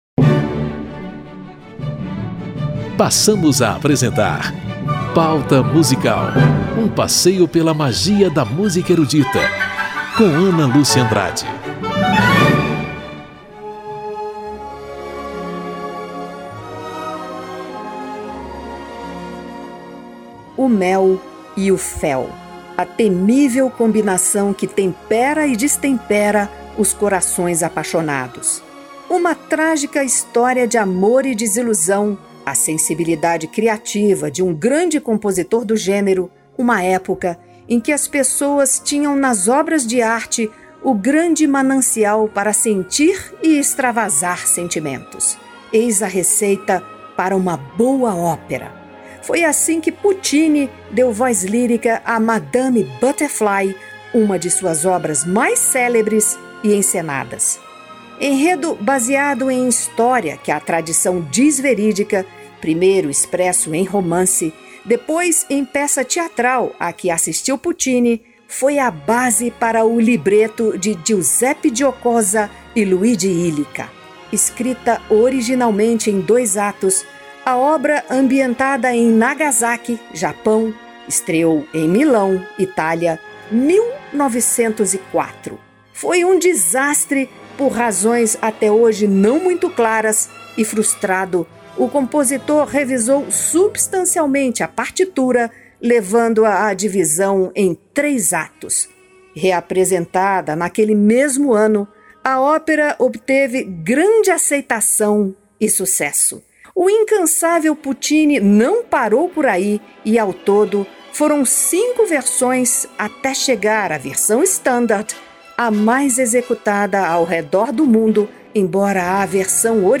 Enredo de amor e desilusão, numa história que revela faces da vergonha e da honra oriental, passada em Nagasaki. Solistas de renome internacional, Coro e Orchestra del Teatro dell'Opera di Roma, sob a regência do maestro Sir John Barbirolli, interpretam a ópera Madame Butterfly, de Giacomo Puccini.